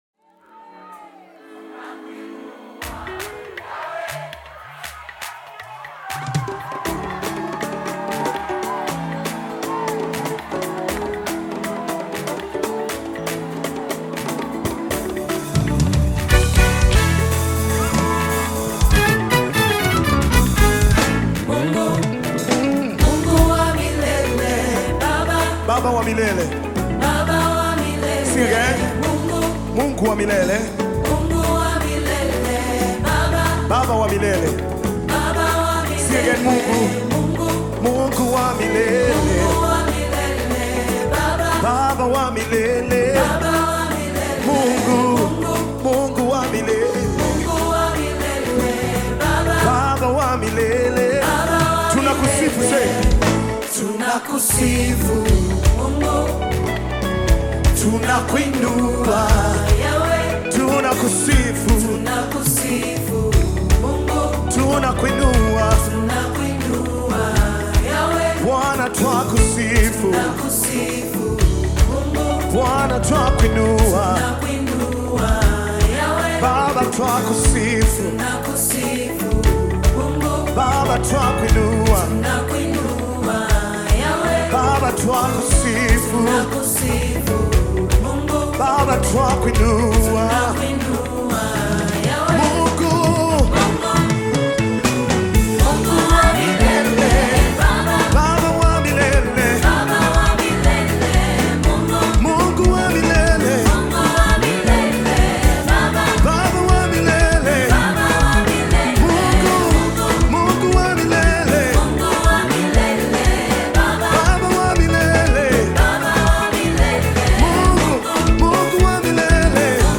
One of the best Tanzanian worship teams
worship song